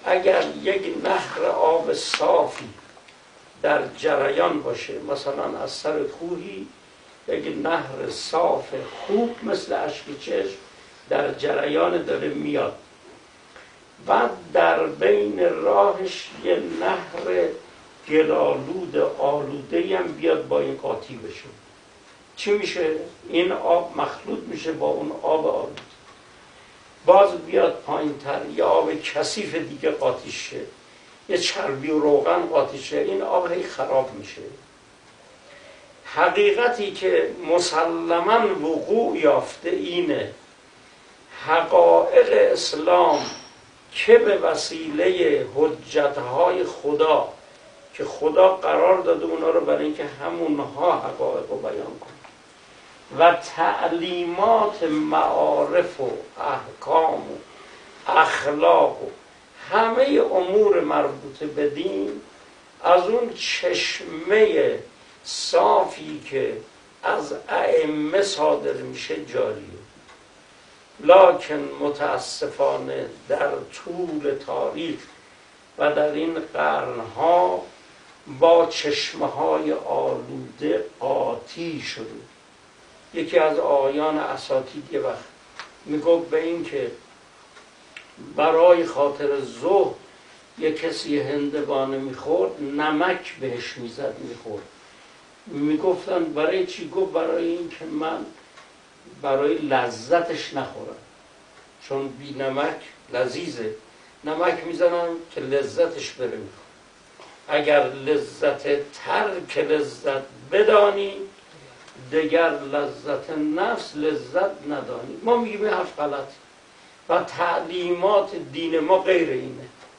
صوت ســـخنرانی:
سخنران: آیت‌الله شریعتمداری.